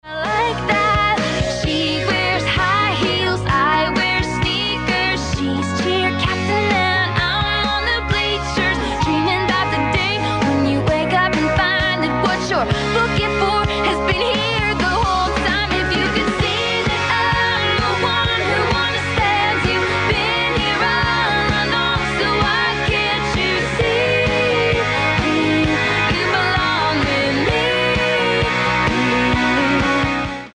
Asymmetrical Modulation AM Samples
The positive peak modulation was allowed to go as high as it wanted at times hitting 129%+ for 98% negative.
Power input was 65 mw at a frequency of 1290 Khz using a legal, 3 meter, non loaded antenna.
The recordings were made using a AWIA model TX706 Walkman style radio as the receiver.
Audio was also loud and clean on a Sony SRF 42, a Sony ICF M410V, a Realistic STA-111, the radio in my Honda CRV or any radio I tried.